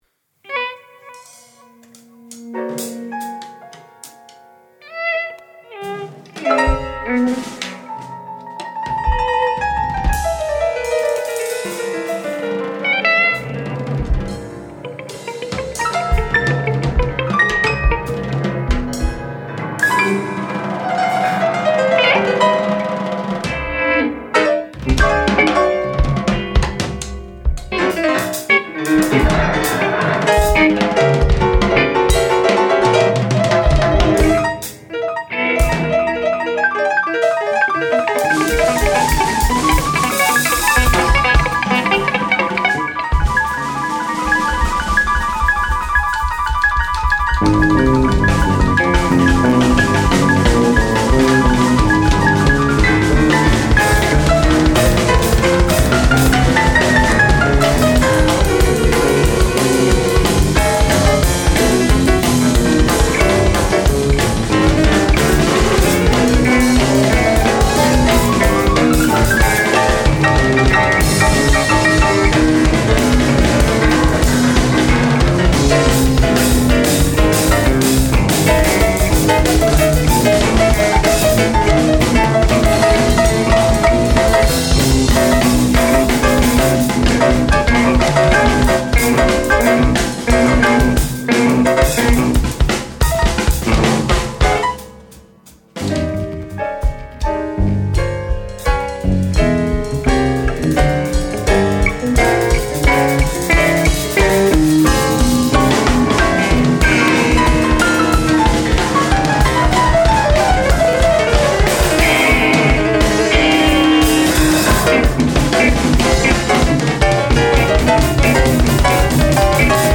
全編即興ながら息をもつかせぬ怒濤の展開から美しいバラードまで一気に聴かせる。